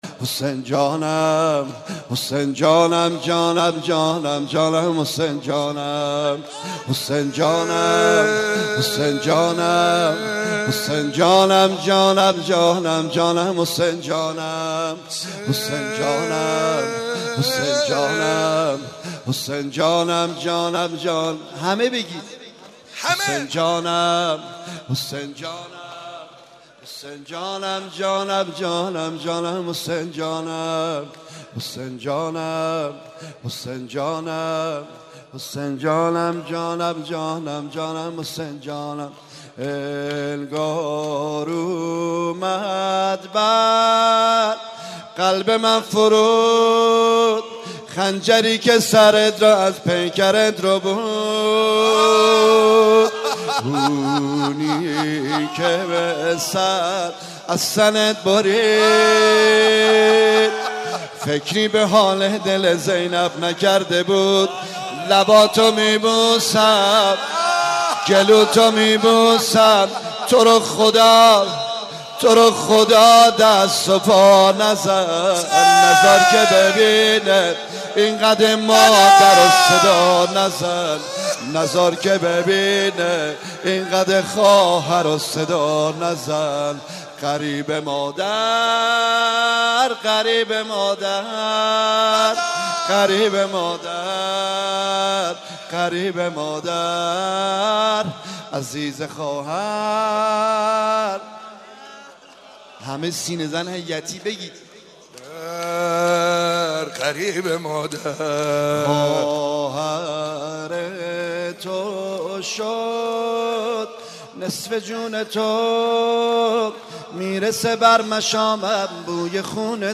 عقیق: در این مراسم تعدادی از ذاکرین اهل بیت(ع) مداحی کردند که در ادامه صوت مداحی ها منتشر می شود:
مداحی
در مراسم ترحیم